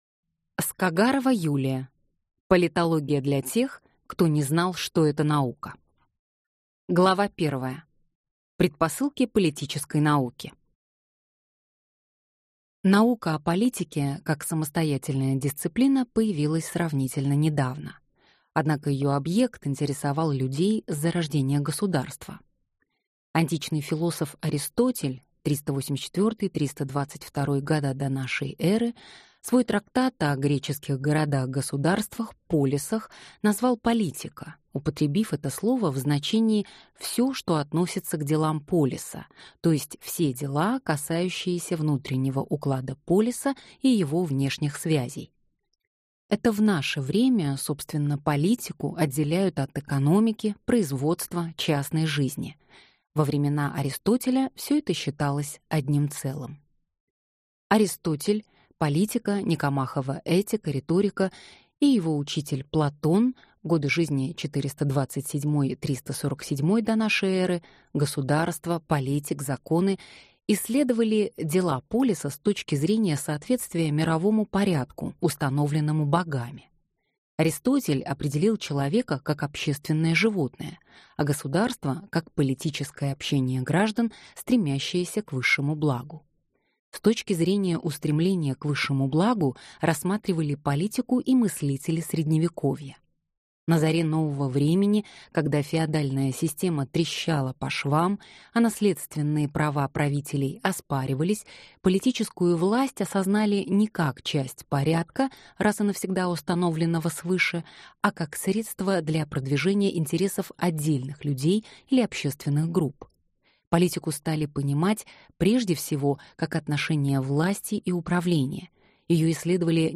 Аудиокнига Политология для тех, кто не знал, что это наука | Библиотека аудиокниг